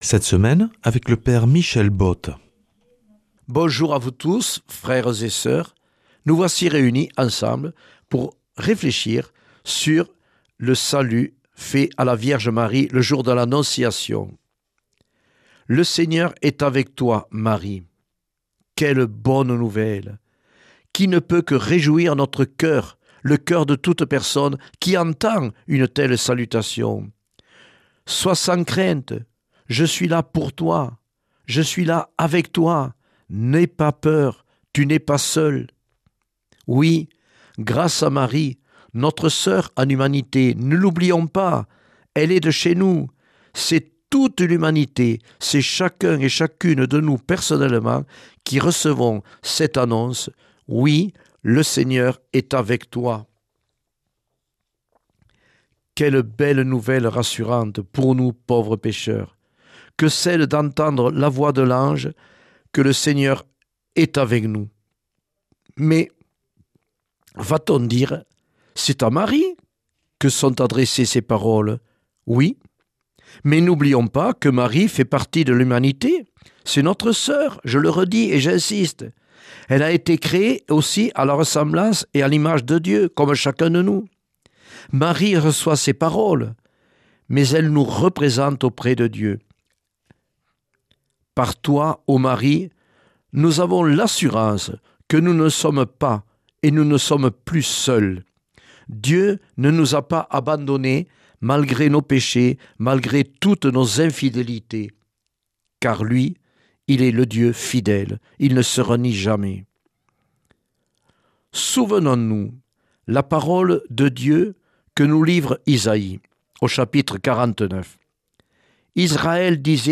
jeudi 9 avril 2026 Enseignement Marial Durée 10 min